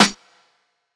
Medicated Snare 24.wav